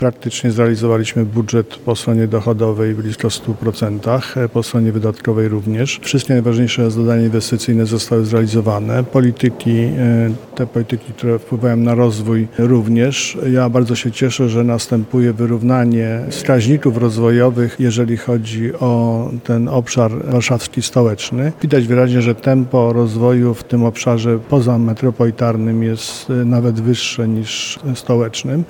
Marszałek województwa Adam Struzik mówił, że to był dobry rok, pełen inwestycji.